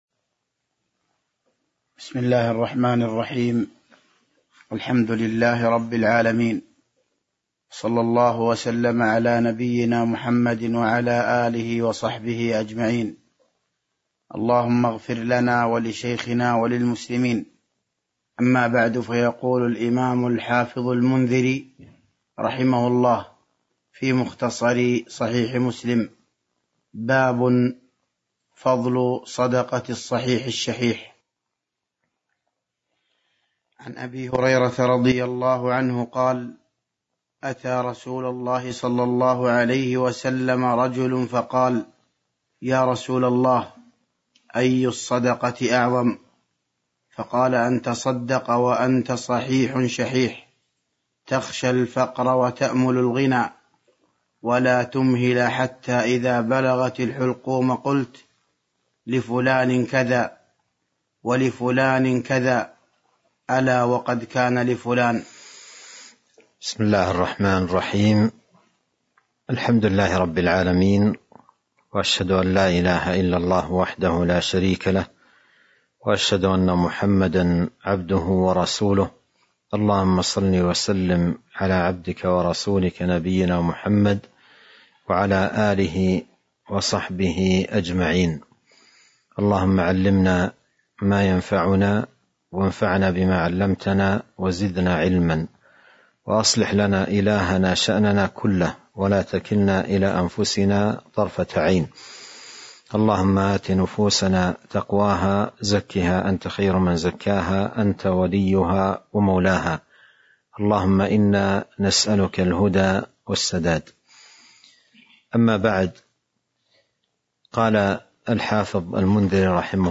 تاريخ النشر ٢٠ رجب ١٤٤٢ هـ المكان: المسجد النبوي الشيخ